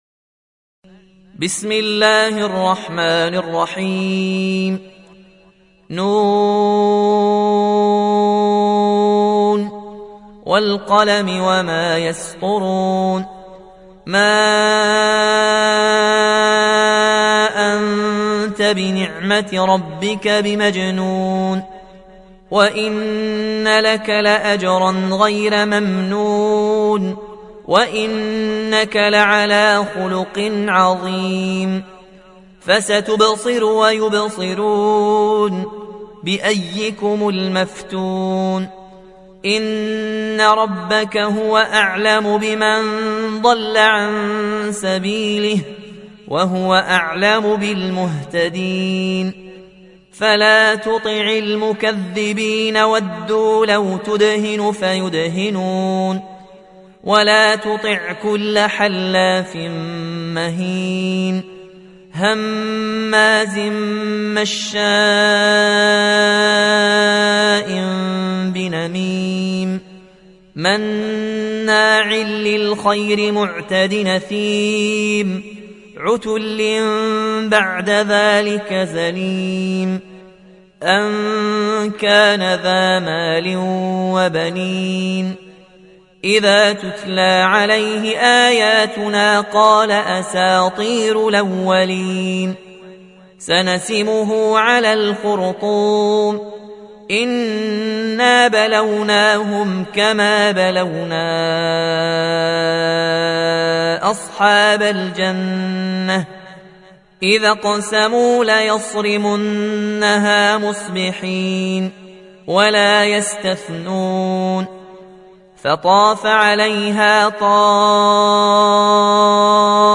Riwayat Warsh dari Nafi